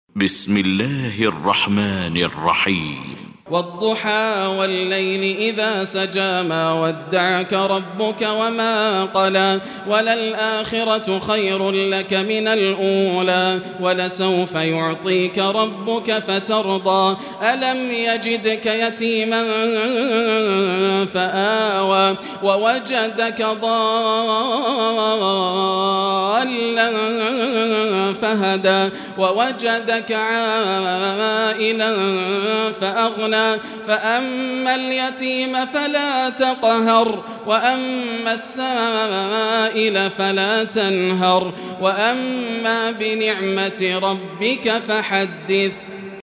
المكان: المسجد الحرام الشيخ: فضيلة الشيخ ياسر الدوسري فضيلة الشيخ ياسر الدوسري الضحى